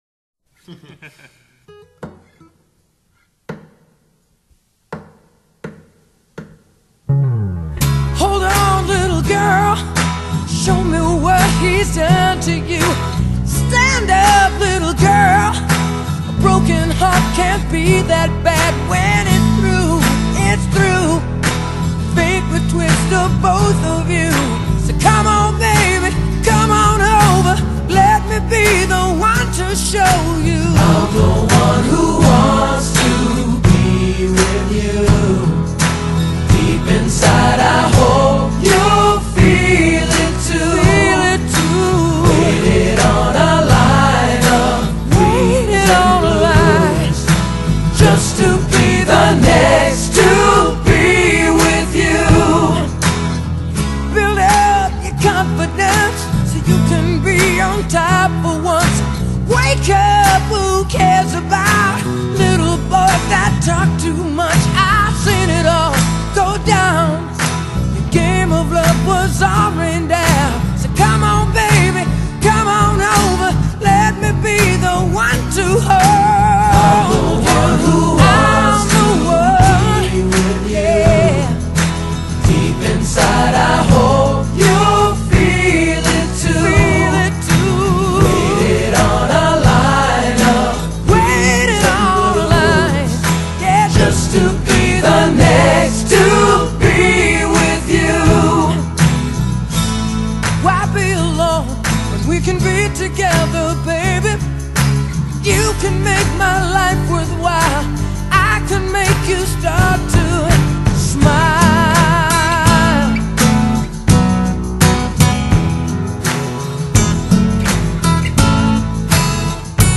Genre: Pop, Soul, R&B, Ballad